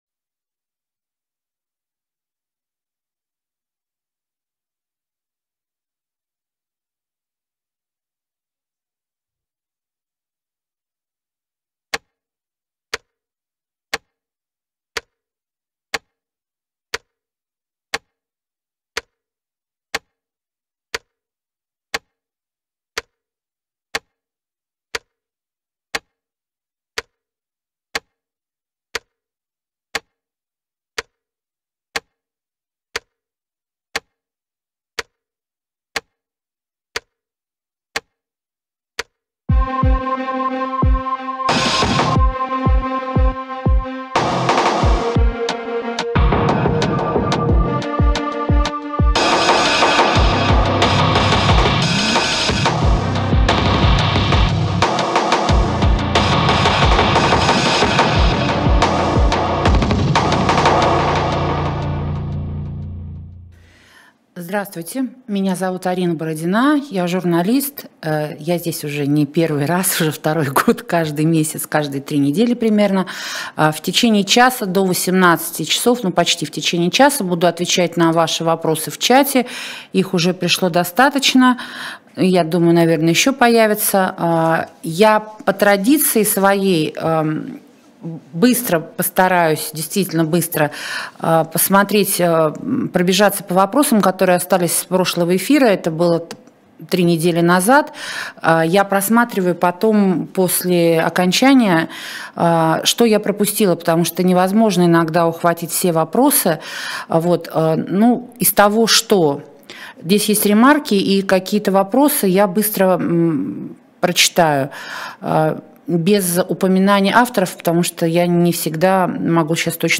Телеобозреватель Арина Бородина отвечает на ваши вопросы в прямом эфире